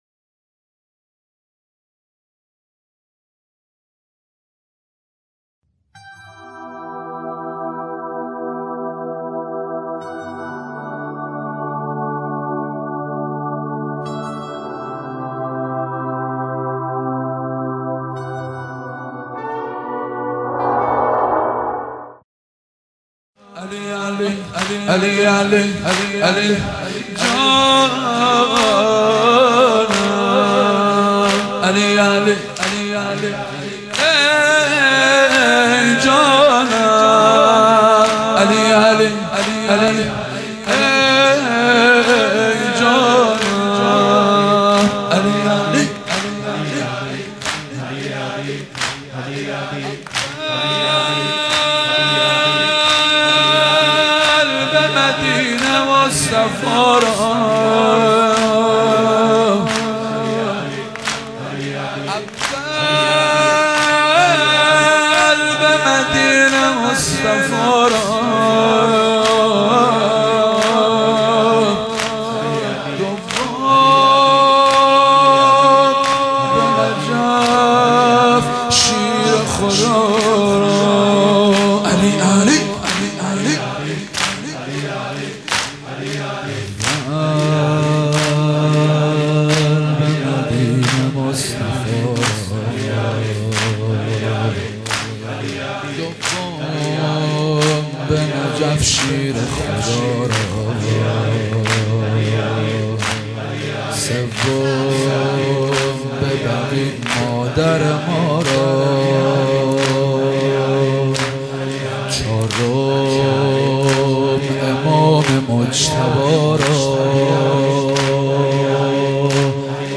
تک | علی جان ای جانم
مداحی
شب بیست و یکم رمضان 95 | هیئت قمر بنی هاشم ساری